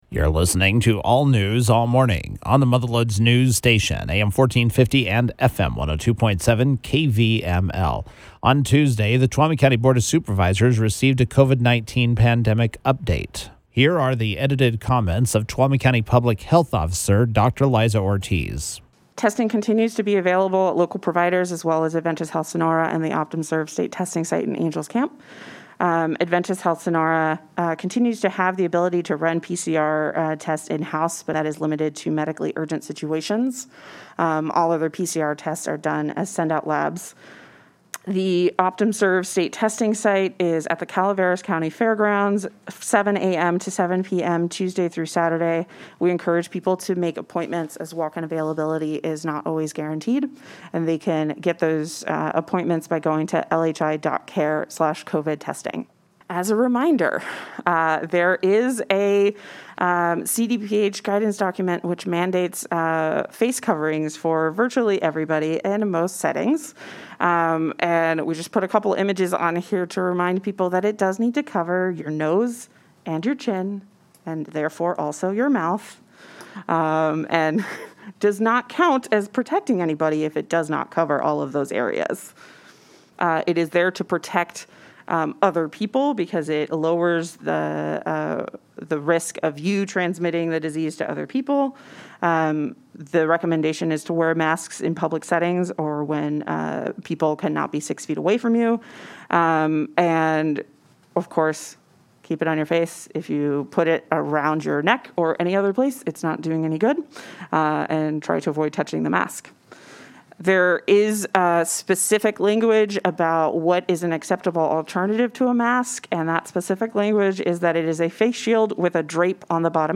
Tuolumne County Public Health Officer Dr. Liza Ortiz spoke before the Tuolumne County Board of Supervisors earlier this week.